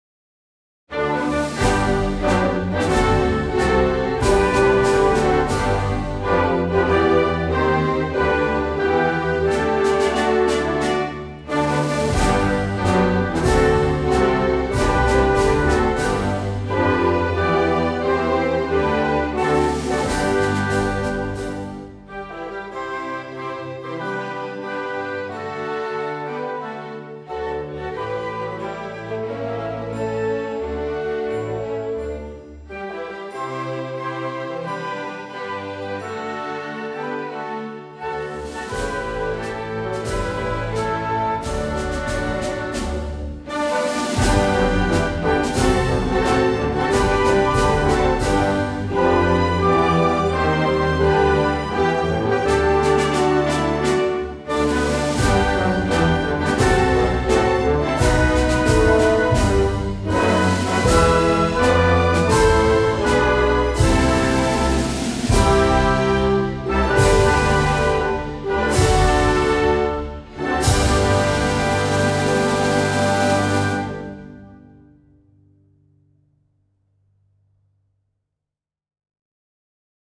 himne_d_andorra_orquestra-wav